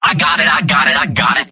Robot-filtered lines from MvM. This is an audio clip from the game Team Fortress 2 .
{{AudioTF2}} Category:Scout Robot audio responses You cannot overwrite this file.